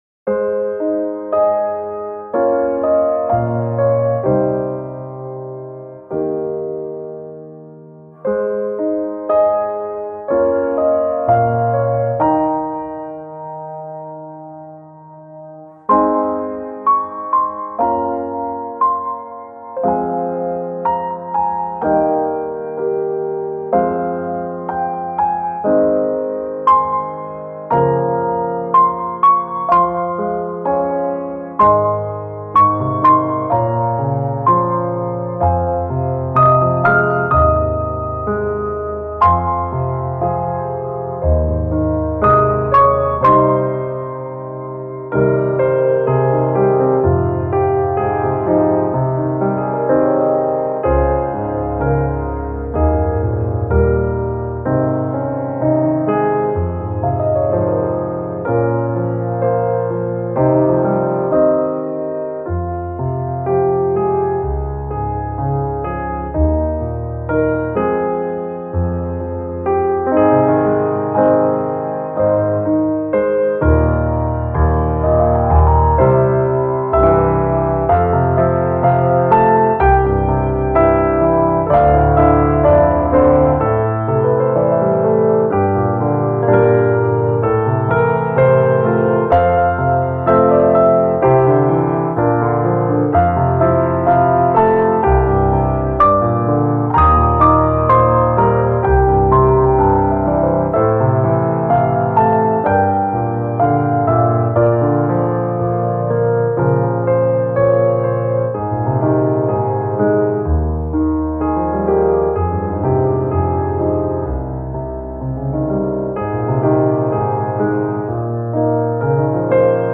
静かな感動や涙を誘うようなシーンに合い、感動的な映像、回想、エンディングなど幅広くご利用いただけます。
ピアノソロ バラード 静か 愛 落ち着く 感動